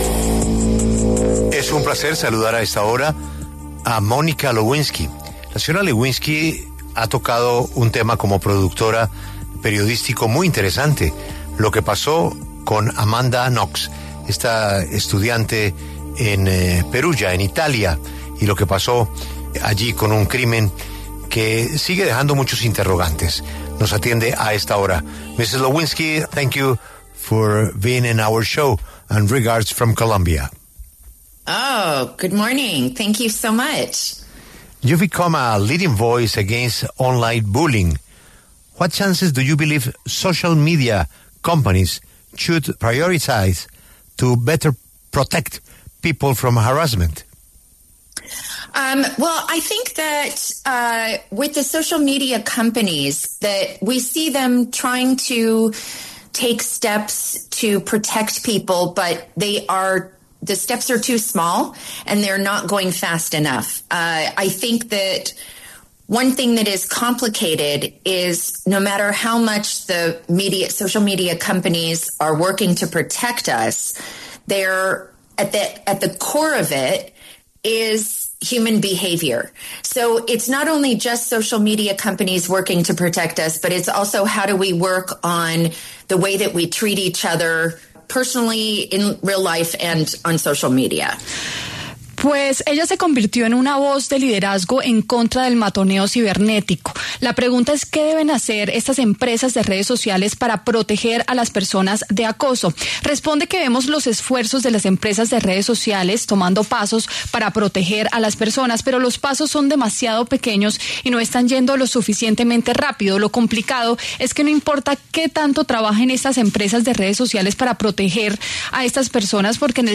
Monica Lewinsky, activista y personalidad de televisión estadounidense, habla en La W a propósito de la serie ‘The Twisted Tale of Amanda Knox’.